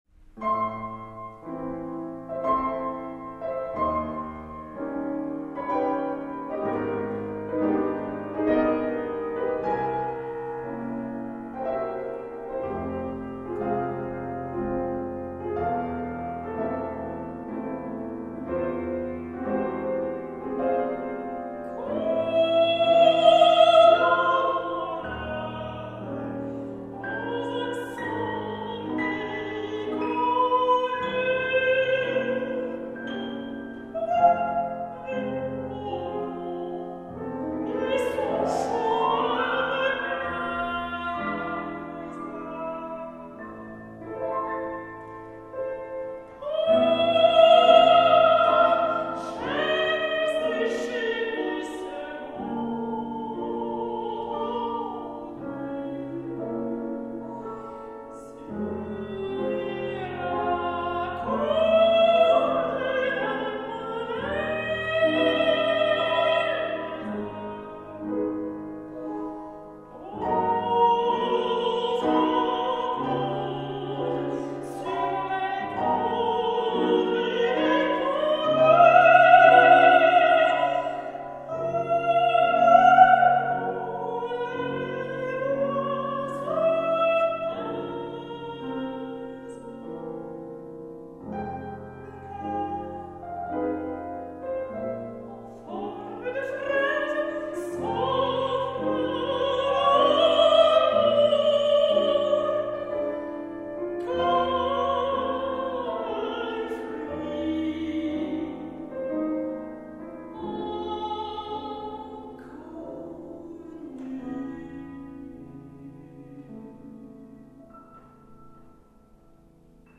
мецо сопрано
пиано